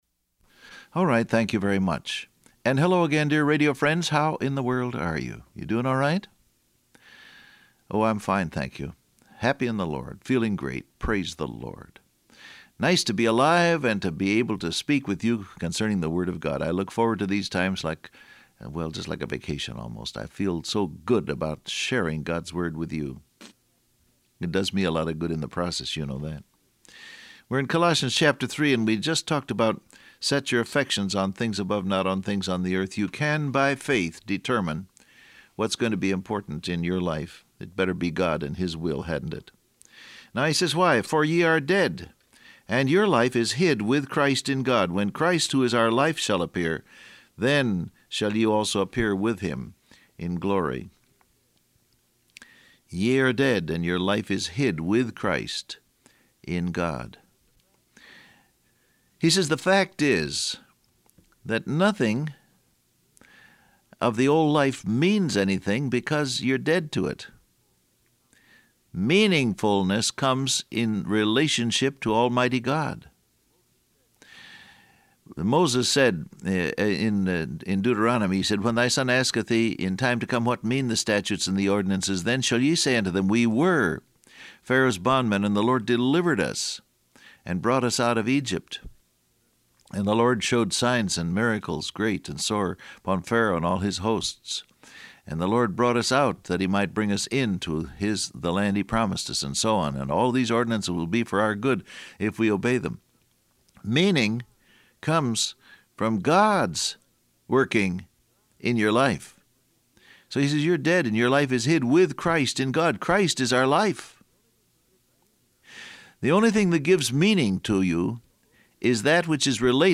Download Audio Print Broadcast #1905 Scripture: Colossians 3:2-10 , Deuteronomy, Philippians 2 Transcript Facebook Twitter WhatsApp Alright, thank you very much, and hello again, dear radio friends.